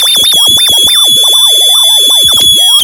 描述：由MuteSynth产生的假短波无线电干扰
Tag: 短波 静音合成器 假短波 干扰 无线电